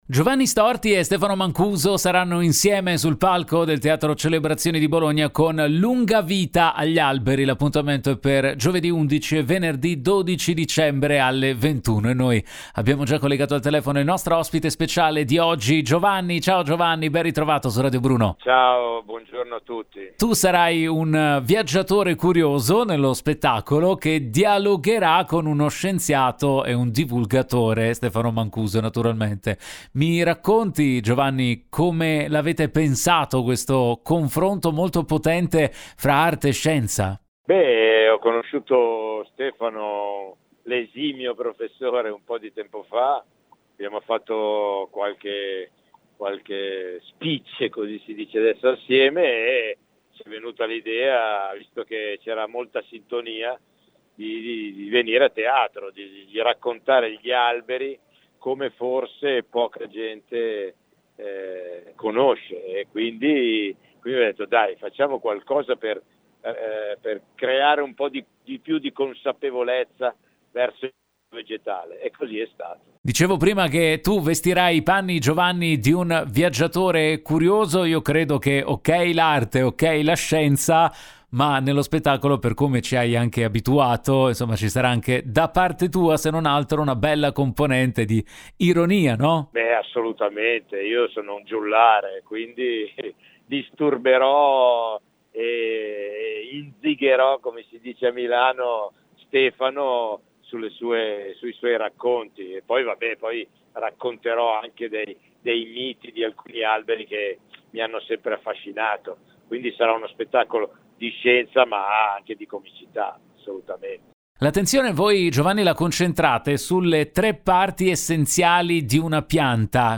Home Magazine Interviste Giovanni Storti e Stefano Mancuso a Bologna con “Lunga vita agli alberi”